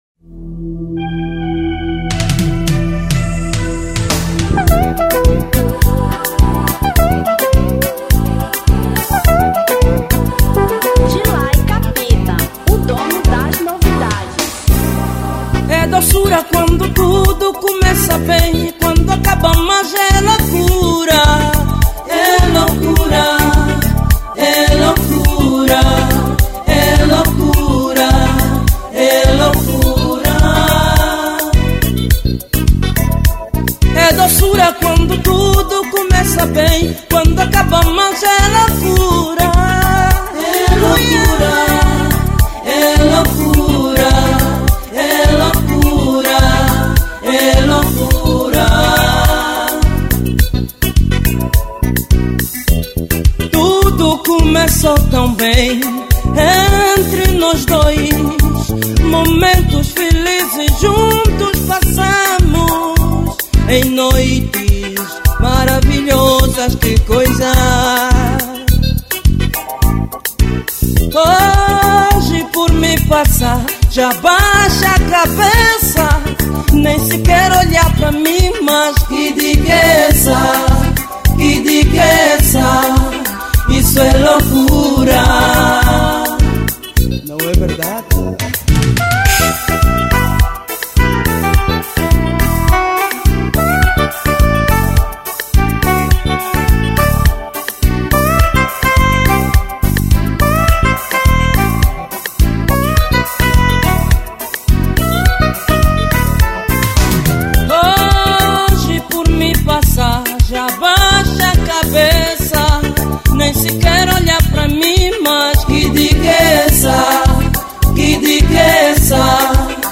Kizomba 1998